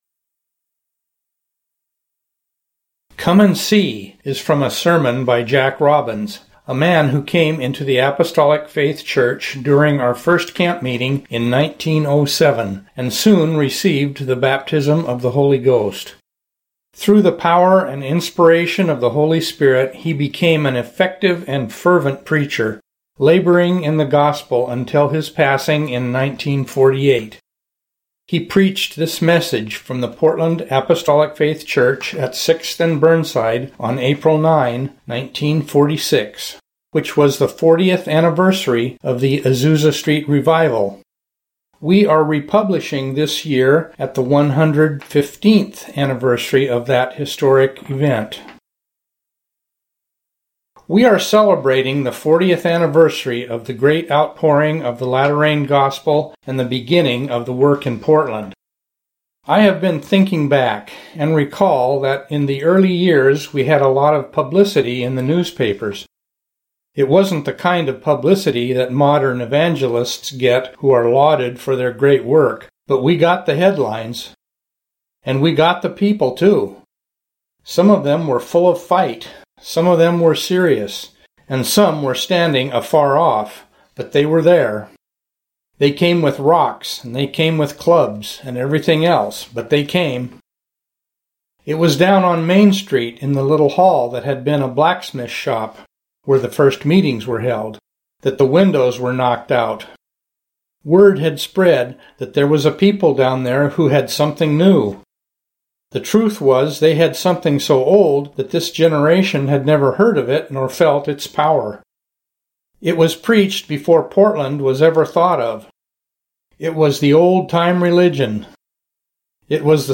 This sermon was originally preached in 1946 at the fortieth anniversary of the Azusa Street Revival. The message was given in the Portland Apostolic Faith Church at Sixth and Burnside.